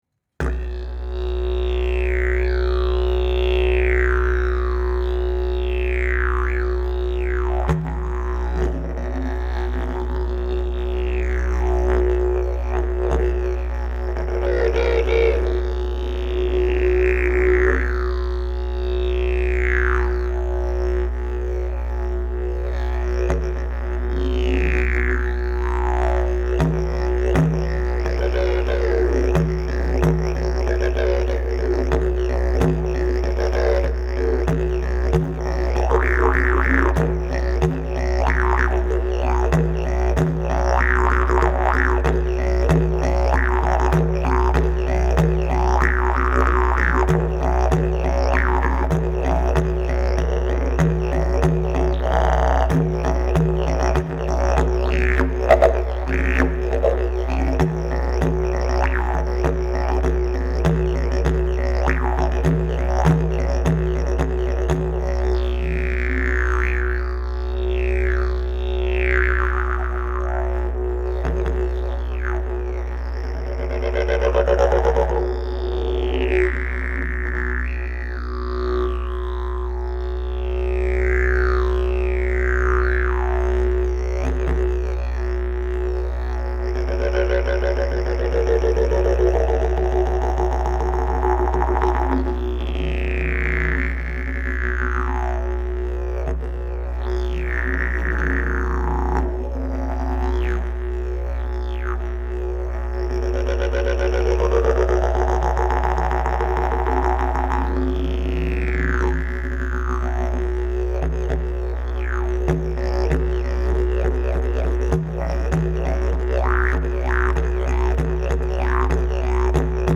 Key: B Length: 68" Bell: 6.5" Mouthpiece: Red Zebrawood, Cherry, Walnut, Granadillo Back pressure: Very strong Weight: 8 lbs Skill level: Intermediate-Advanced
Didgeridoo #645 Key: B